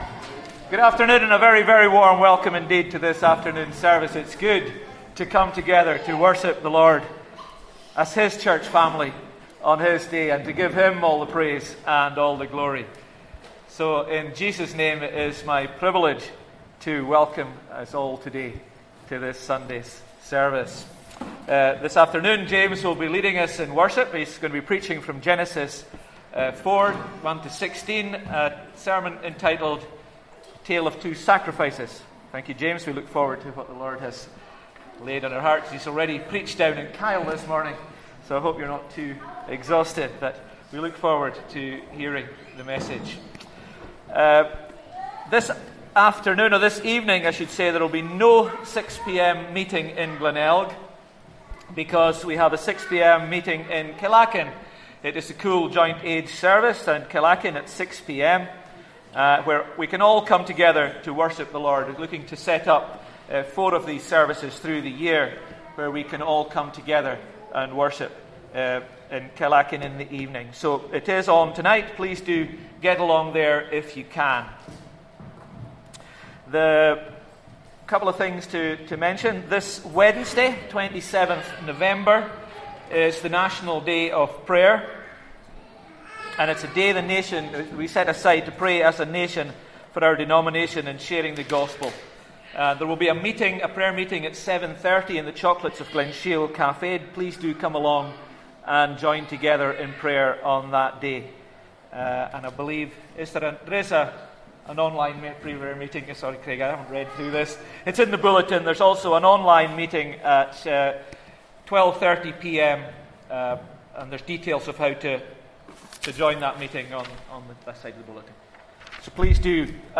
Sunday Service 24th November